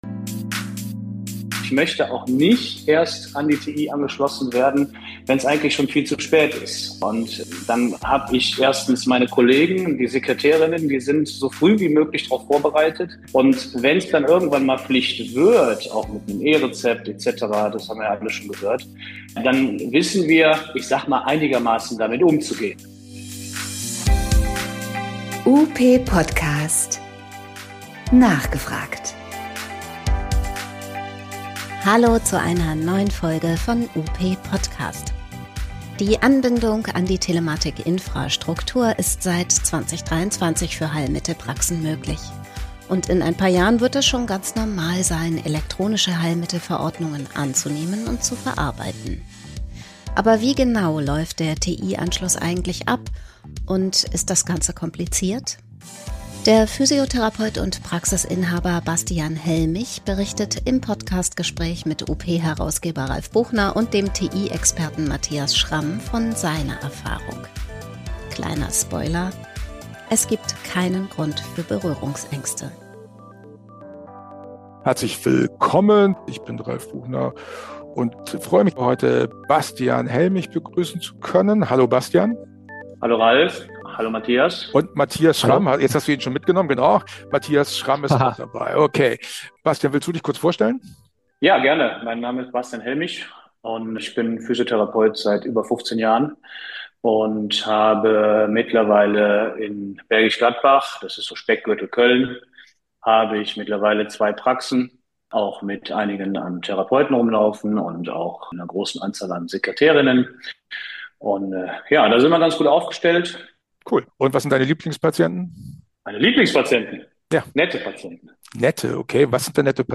Die Telematikinfrastruktur-Anbindung für Heilmittelpraxen läuft. Im Podcast berichtet ein Praxisinhaber von seiner Anschlusserfahrung und beruhigt: das ist einfacher als gedacht.